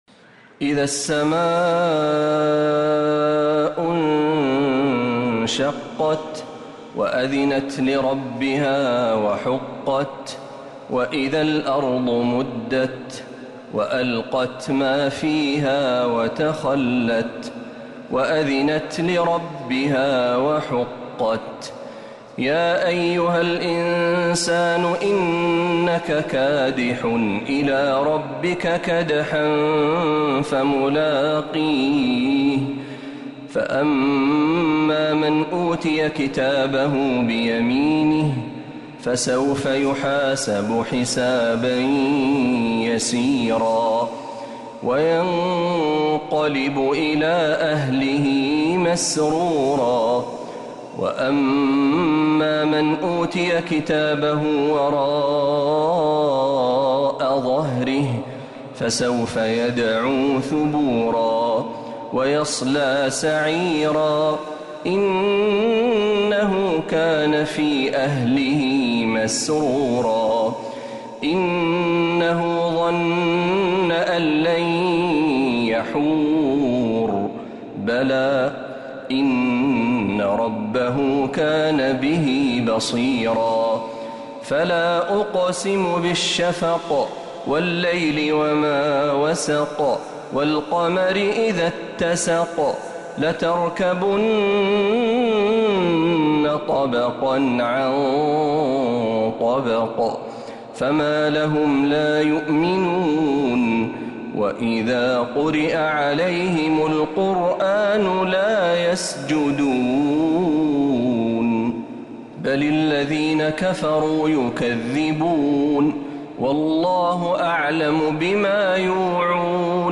سورة الانشقاق كاملة من الحرم النبوي
تلاوات الحرمين